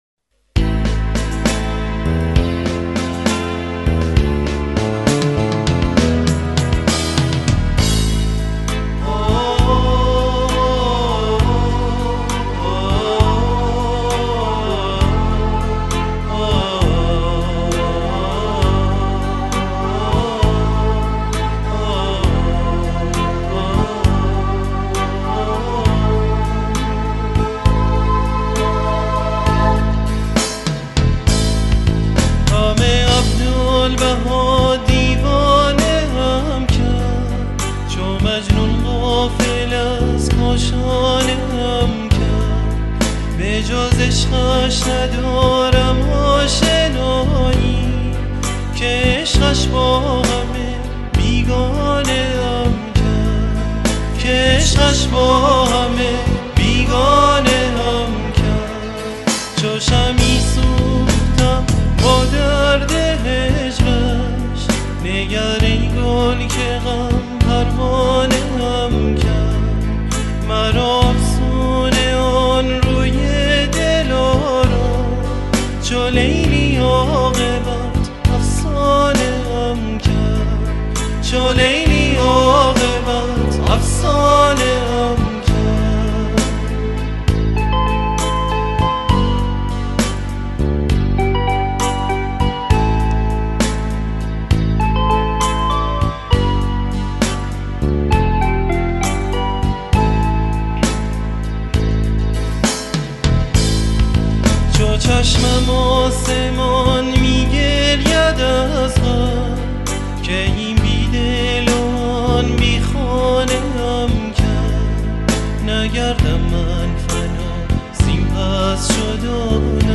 سرود - شماره 6 | تعالیم و عقاید آئین بهائی